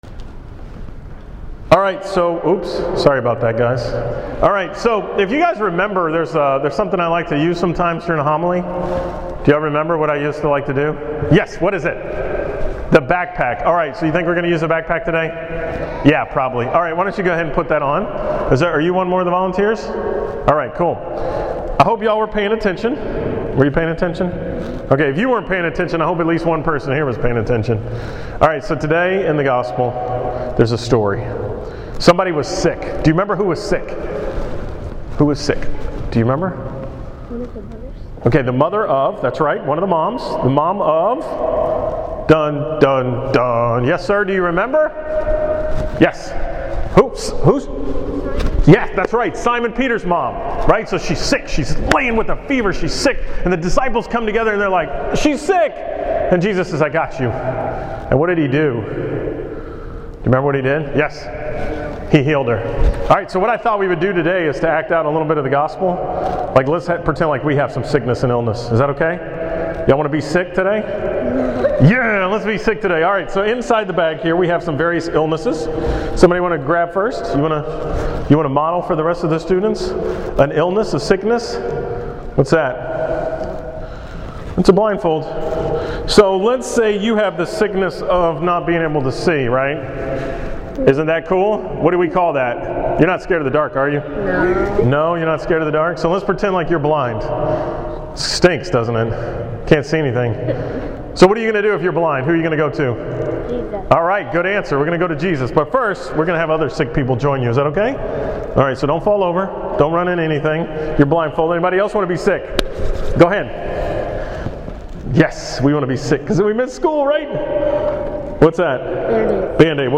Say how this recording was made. From the school Mass at St. Mary Magdalene on January 14, 2015